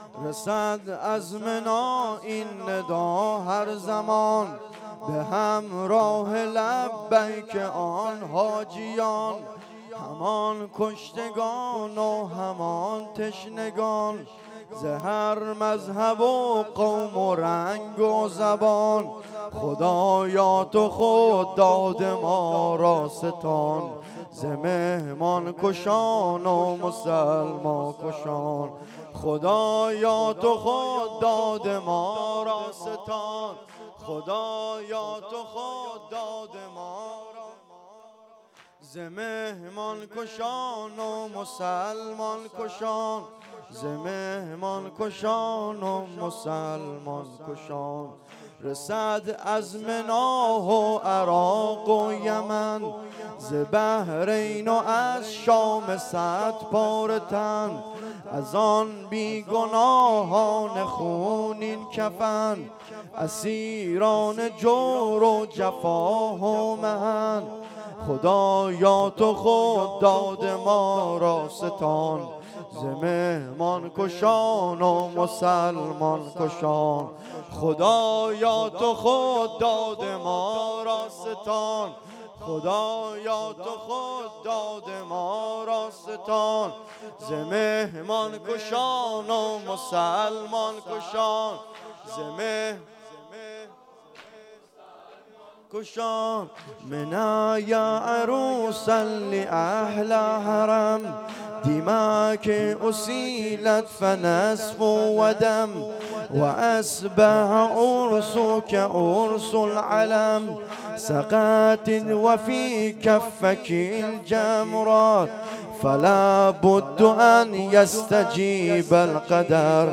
واحد شور